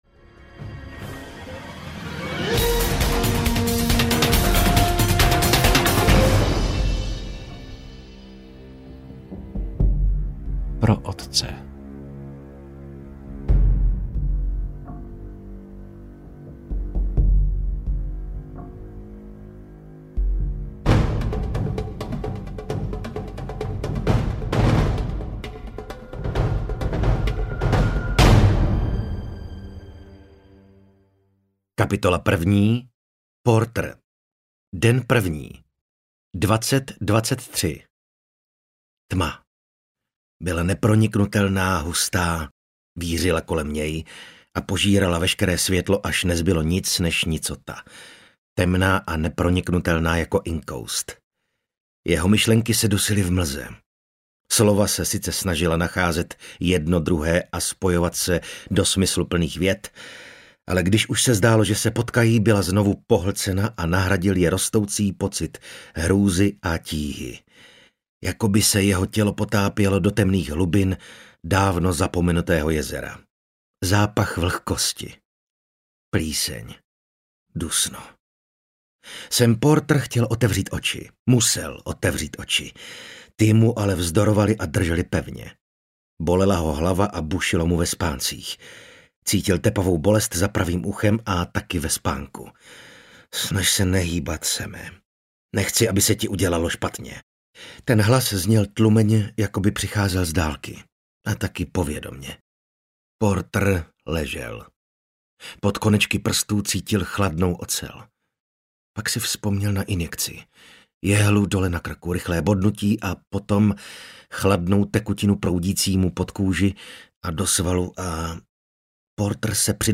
Pátá oběť audiokniha
Ukázka z knihy